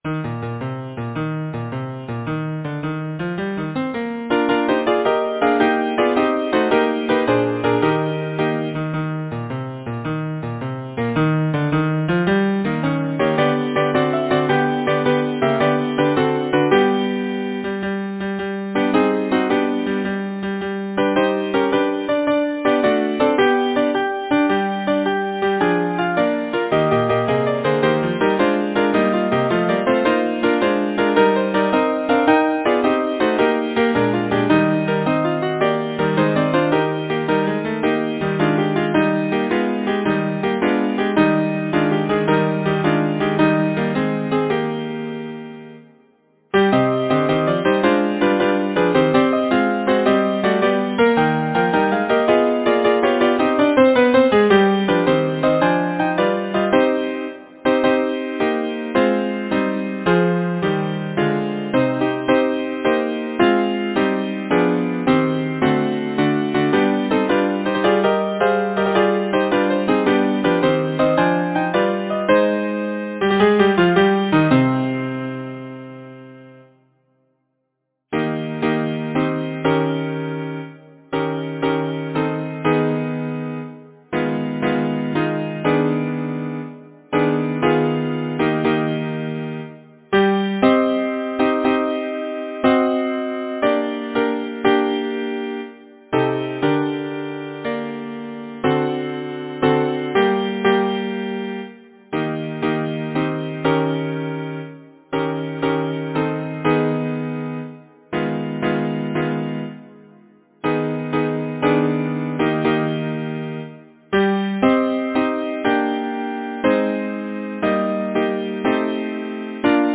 Title: Out on the waters Composer: Alfred James Caldicott Lyricist: John Askham Number of voices: 4vv Voicing: SATB Genre: Secular, Partsong
Language: English Instruments: A cappella